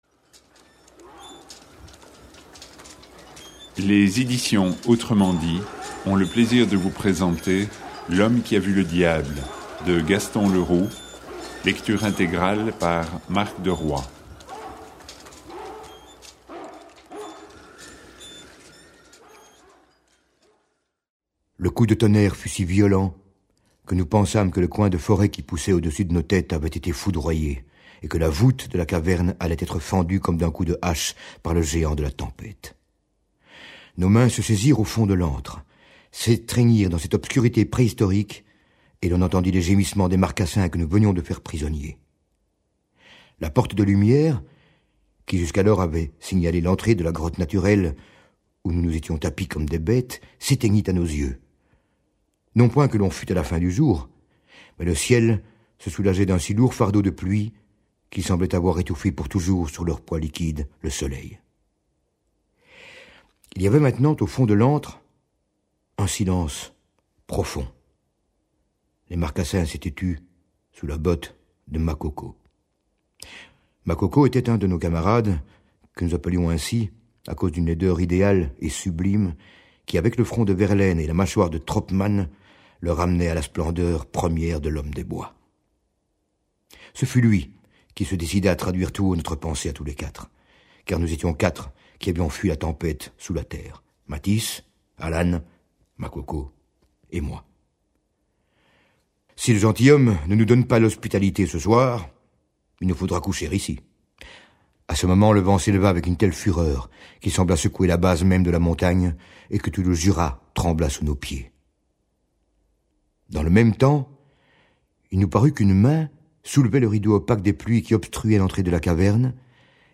Click for an excerpt - L'Homme qui a vu le Diable ! de Gaston Leroux